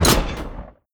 EXPLOSION_Arcade_06_mono.wav